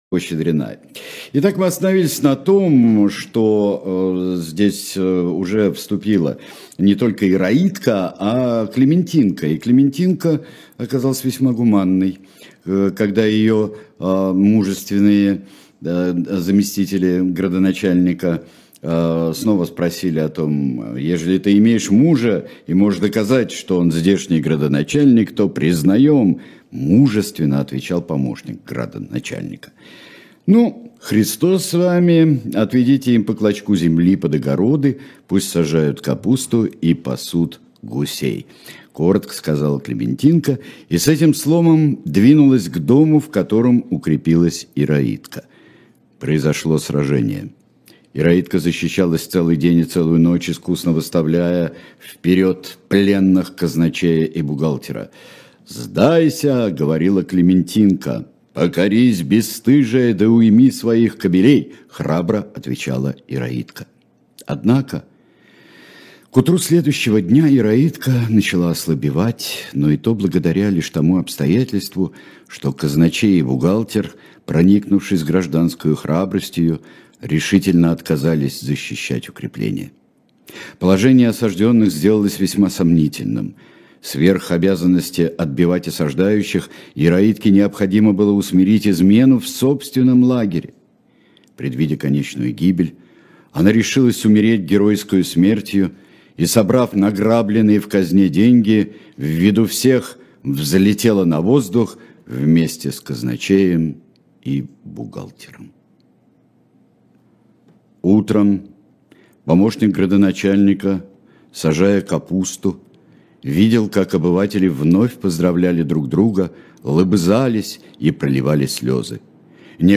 Сергей Бунтман читает книгу Салтыкова-Щедрина «История одного города».